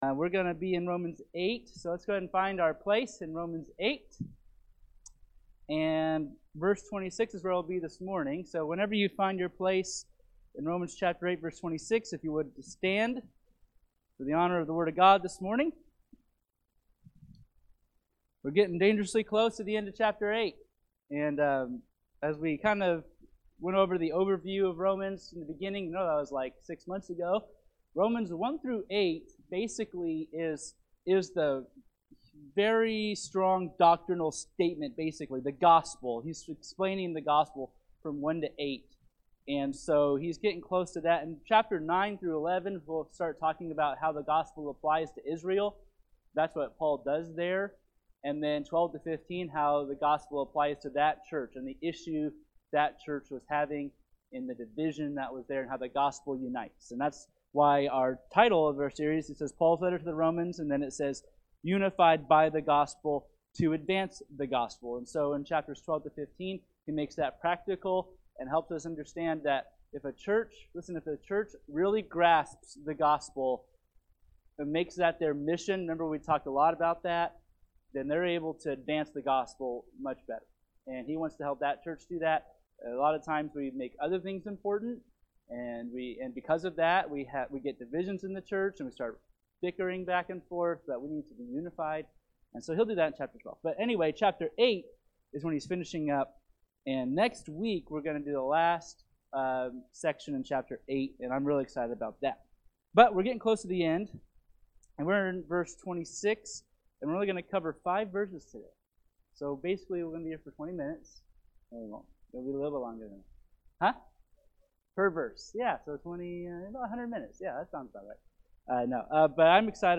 Romans (Sunday School)